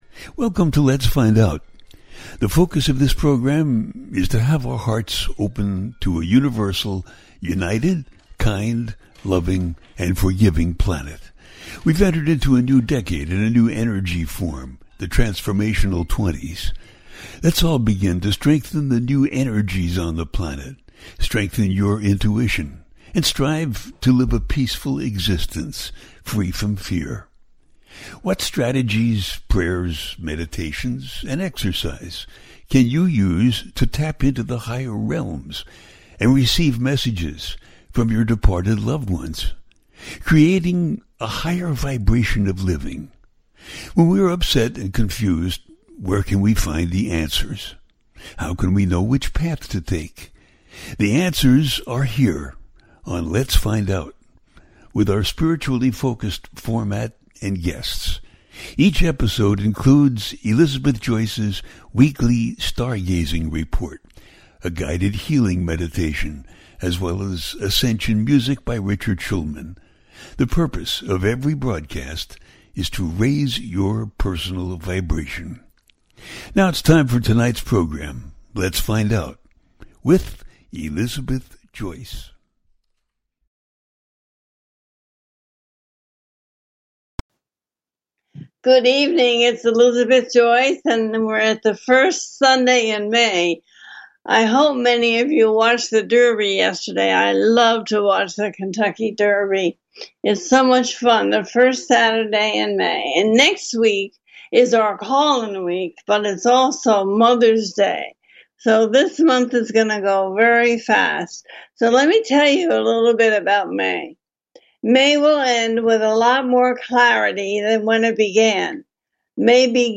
Projection for May-Taurus New Moon, 2024, A teaching show.
The listener can call in to ask a question on the air.
Each show ends with a guided meditation.